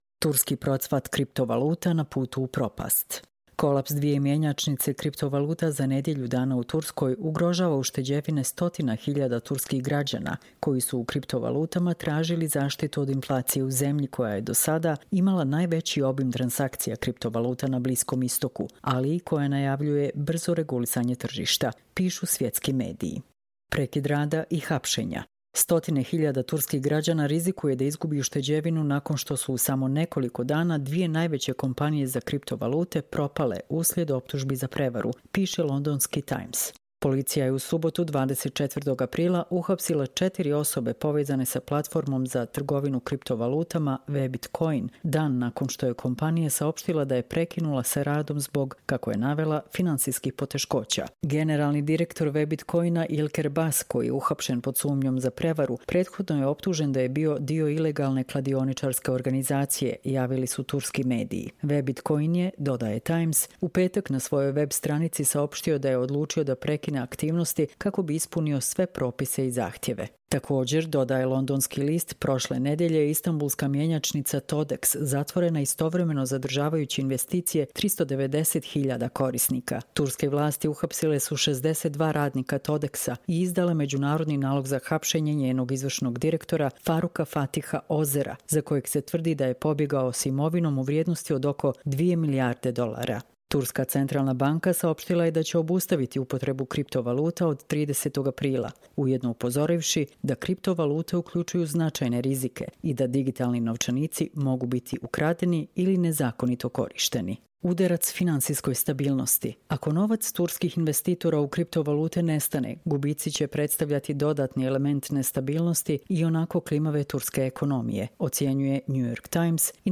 Čitamo vam: Turski procvat kriptovaluta na putu u propast